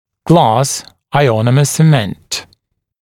[glɑːs aɪˈɔnəmə sə’ment][гла:с айˈонэмэ сэ’мэнт]стеклоиономерный цемент